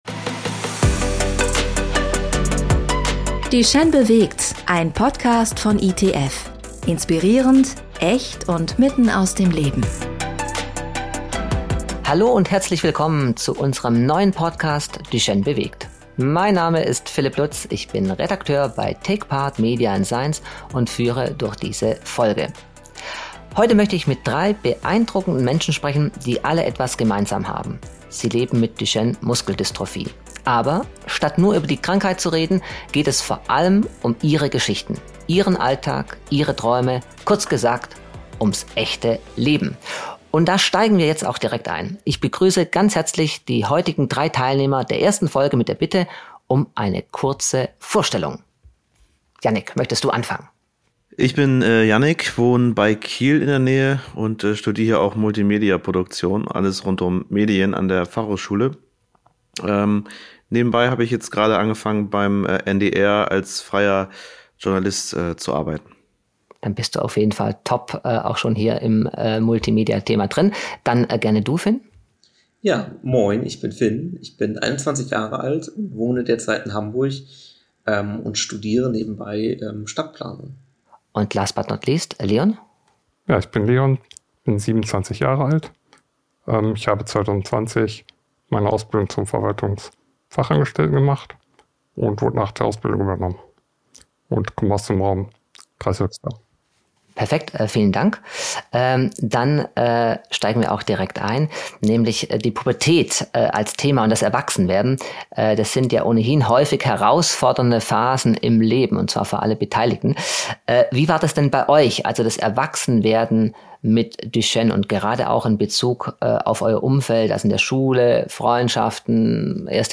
Erwachsenwerden ist nie einfach - und mit Duchenne bringt es noch einmal ganz eigene Herausforderungen mit sich. In dieser Folge von „Duchenne bewegt“ sprechen 3 junge Erwachsene, die mit Duchenne leben, über Fragen, die viele Betroffene beschäftigen: Schule, Freundschaften, Pubertät, erste Liebe.